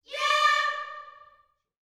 YEAH D 5C.wav